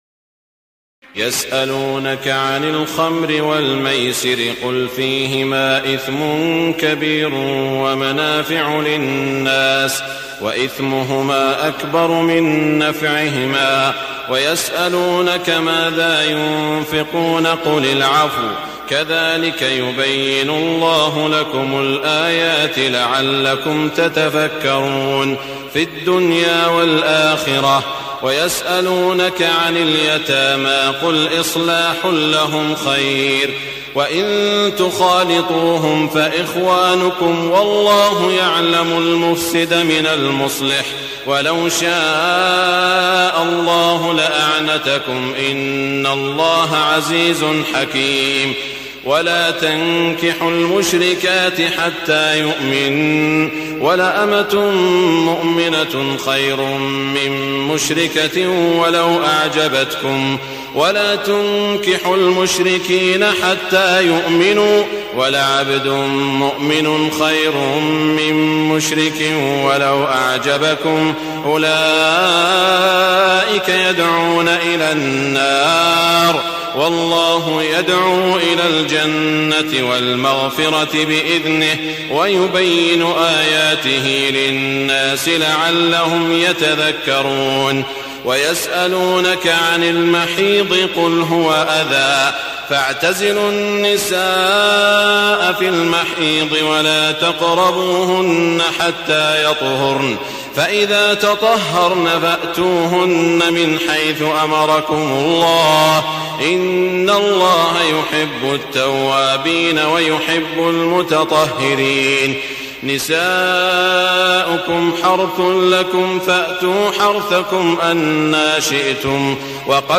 تهجد ليلة 22 رمضان 1422هـ من سورة البقرة (219-253) Tahajjud 22 st night Ramadan 1422H from Surah Al-Baqara > تراويح الحرم المكي عام 1422 🕋 > التراويح - تلاوات الحرمين